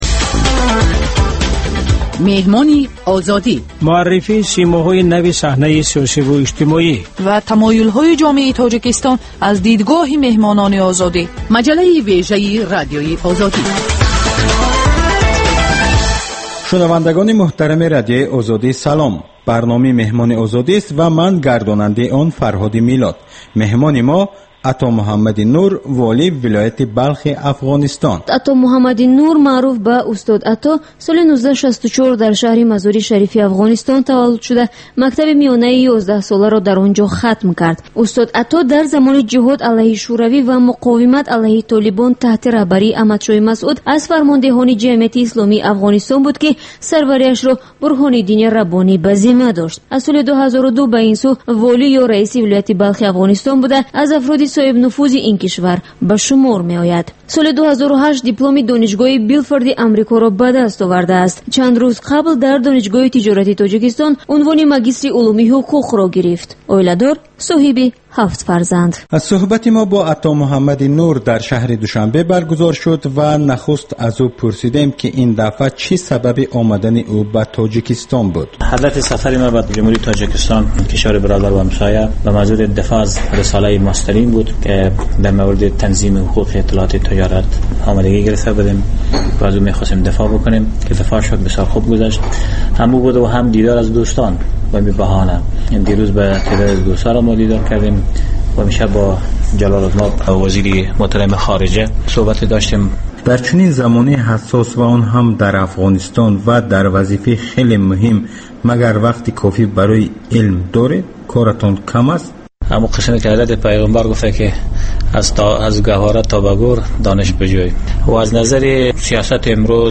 Гуфтугӯи ошкоро бо чеҳраҳои саршинос, намояндагони риштаҳои гуногун бо пурсишҳои ғайриодӣ.